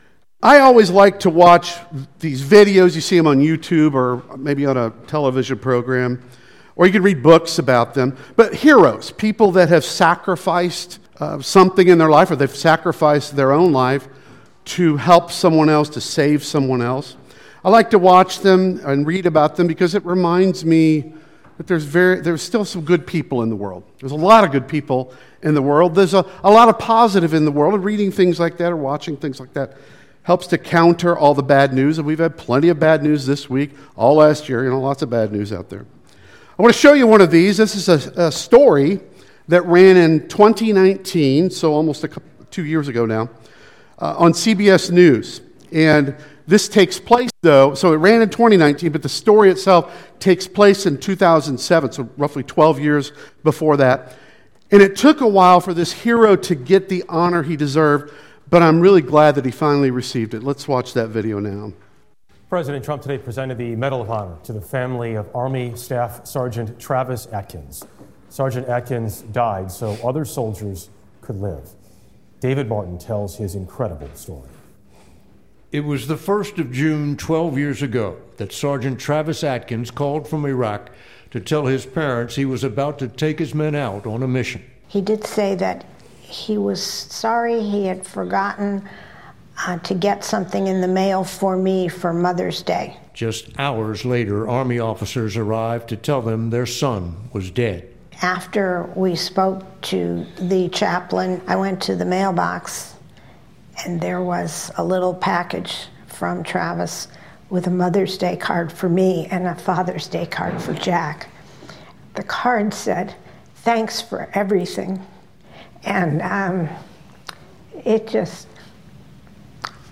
Passage: Hebrews 9 Service Type: Sunday Worship Service